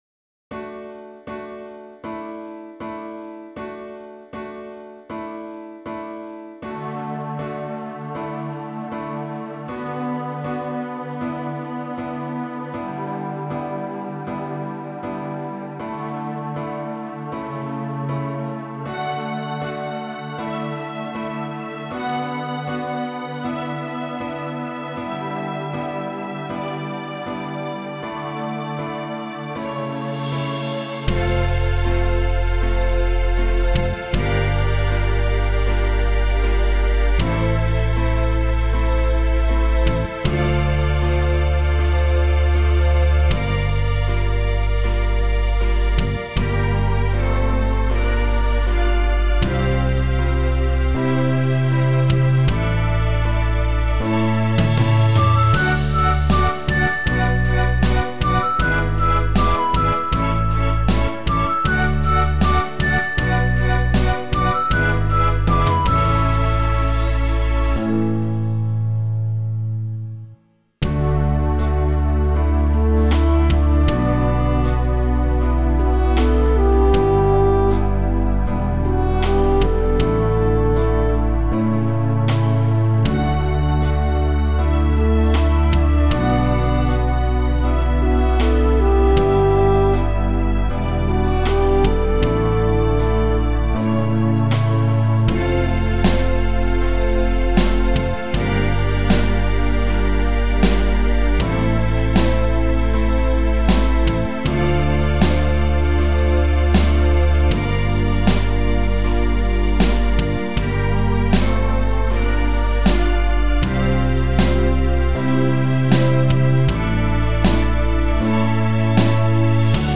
karaoke verziója